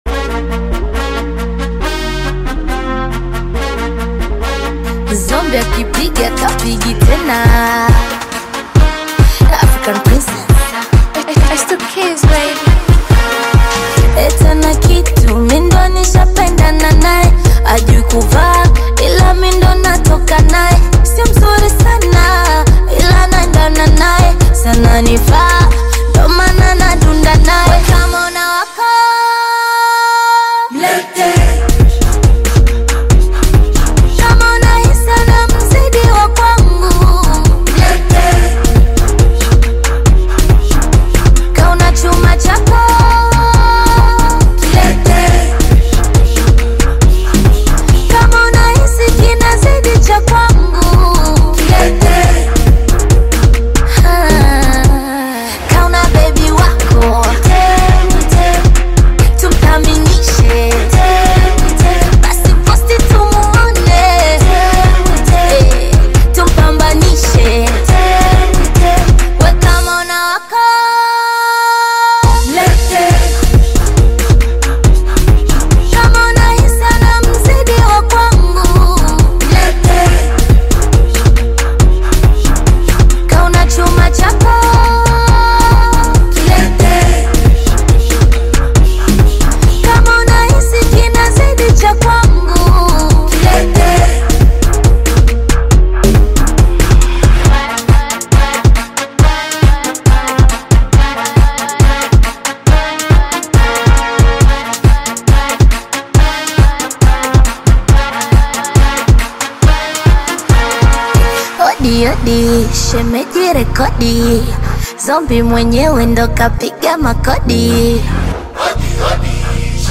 Afro-Pop/Bongo Flava single